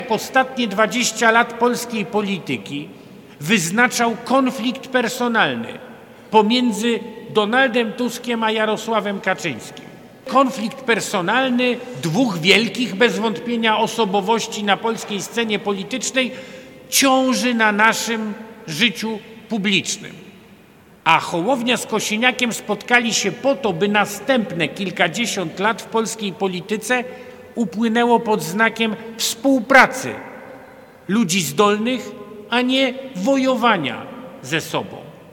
Podczas dzisiejszej konwencji, Trzecia Droga, czyli koalicja Polskiego Stronnictwa Ludowego i Polski 2050 Szymona Hołowni przedstawiła swoich kandydatów na radnych Sejmiku Województwa Zachodniopomorskiego. Gościem specjalnym był wicemarszałek Senatu Michał Kamiński.